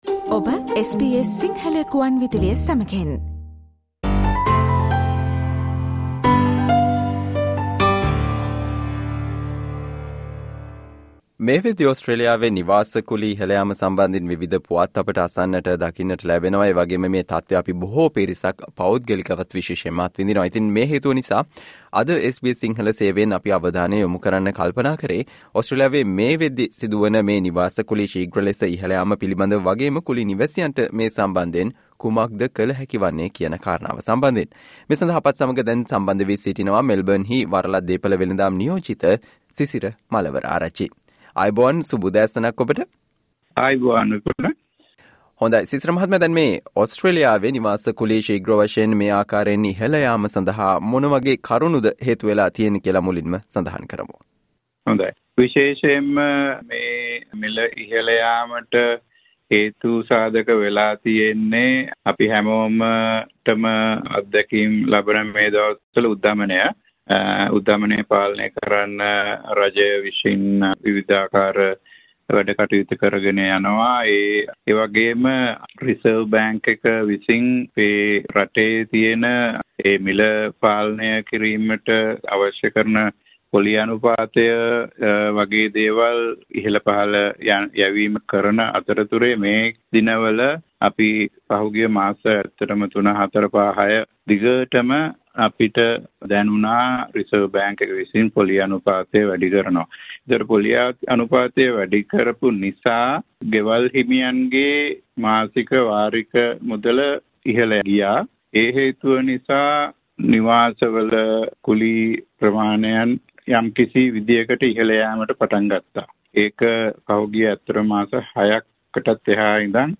ඕස්ට්‍රේලියාවේ මේ වනවිට සිදු වන නිවාස කුලී ශීග්‍ර ලෙස ඉහල යාම සහ මෙමගින් පීඩාවට පත් කුලී නිවැසියන්ට ඊට ප්‍රතිචාර දැක්වීමට තිබෙන අයිතිවාසිකම් පිළිබඳව SBS සිංහල සේවය සිදු කල සාකච්චාවට සවන් දෙන්න